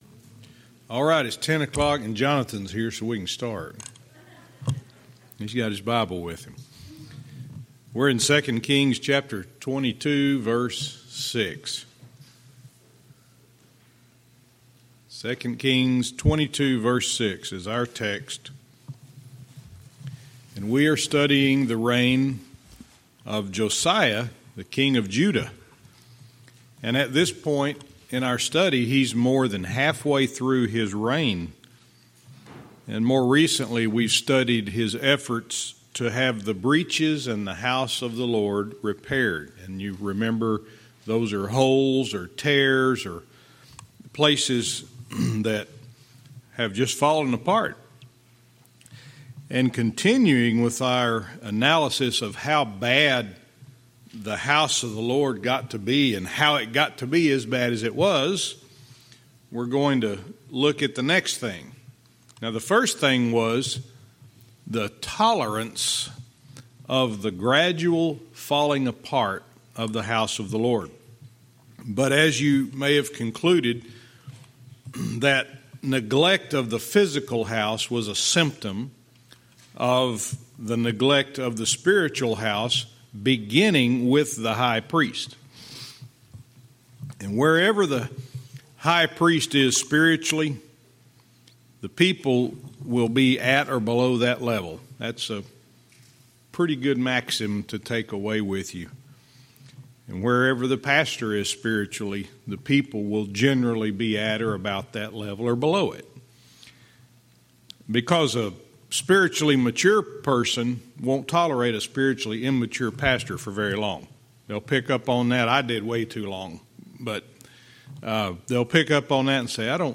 Verse by verse teaching - 2 Kings 22:6-8